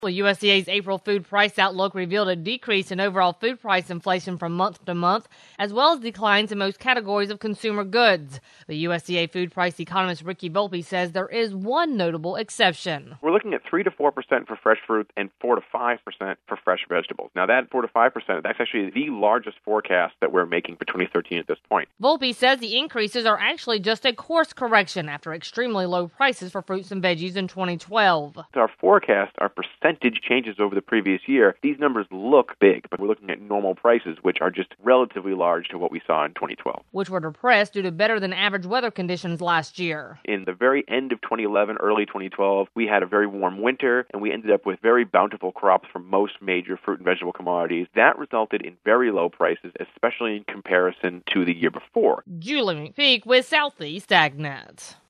A USDA Economist explains why what appears to be a large increase in fruit and vegetable prices isn’t as large as it seems.